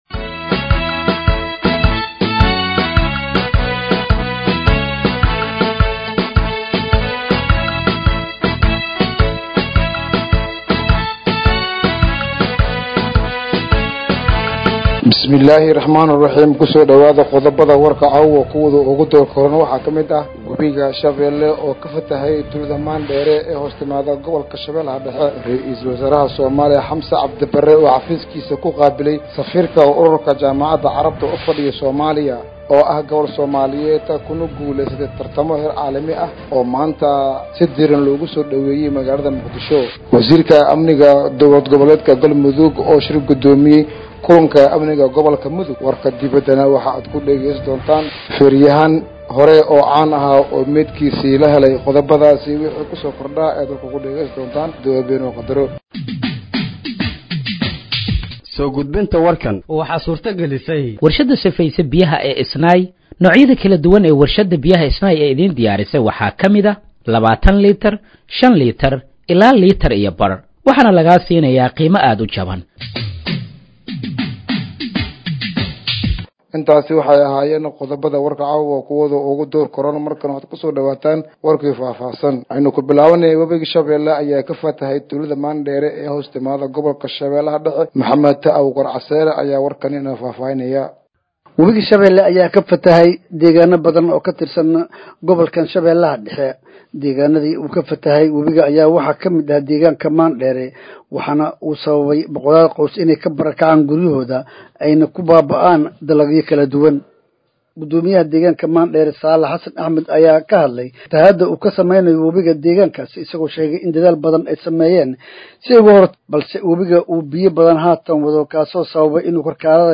Dhageeyso Warka Habeenimo ee Radiojowhar 14/09/2025